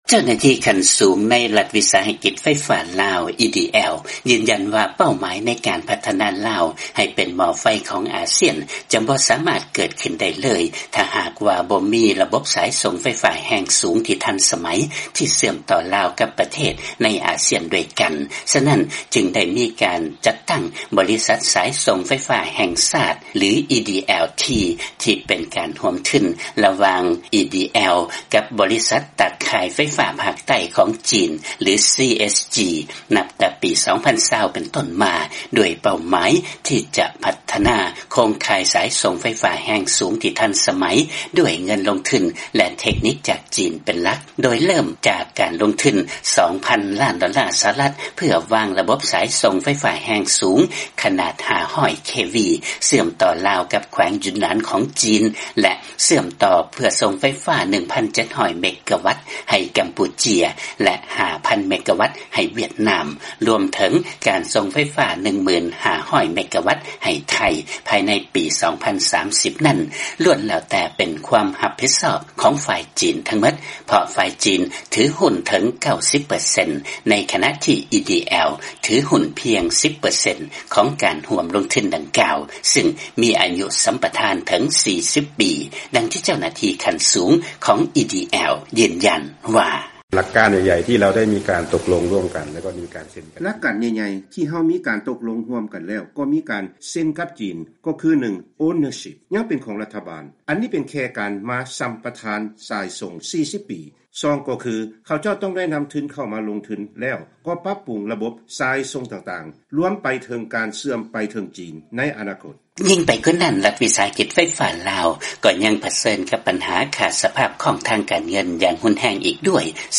ມີລາຍງານຈາກບາງກອກ